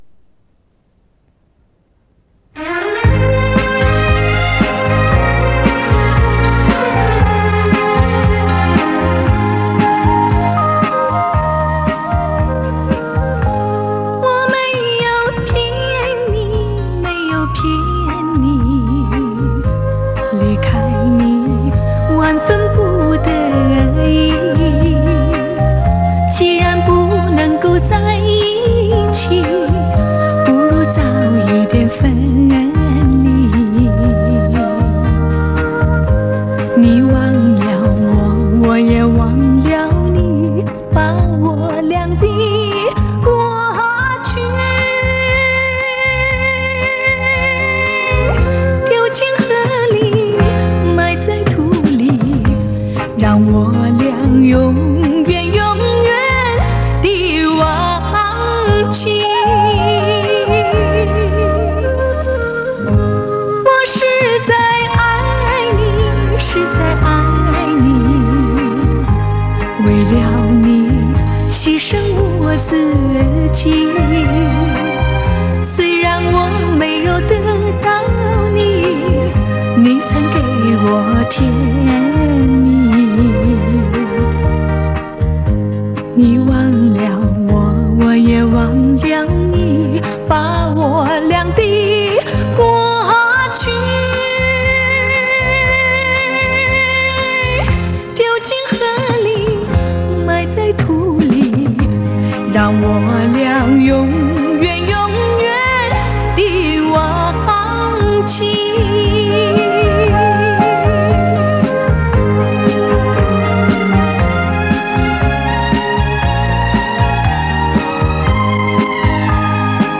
真是非常非常像，咬字、发声以及一些细节。